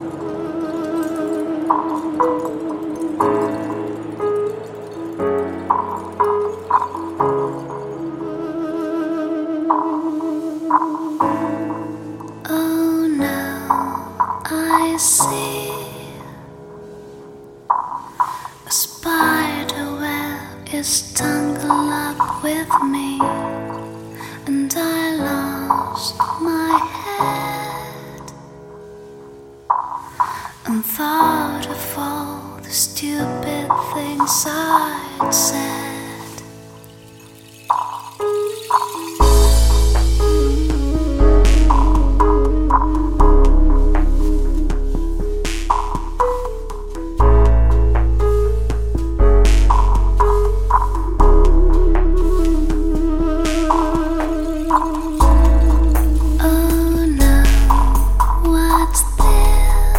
Электронная
Ремиксы в стиле chill-out на такие известные песни